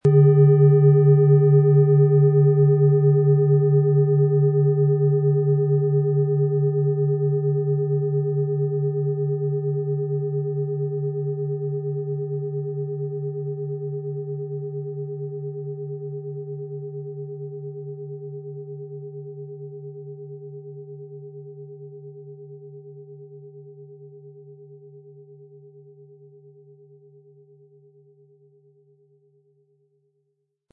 Handgearbeitete tibetische Schale mit dem Planetenton Merkur.
Mit einem sanften Anspiel "zaubern" Sie aus der Merkur mit dem beigelegten Klöppel harmonische Töne.
PlanetentonMerkur
MaterialBronze